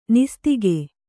♪ nistige